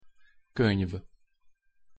Pronunciation Hu Könyv (audio/mpeg)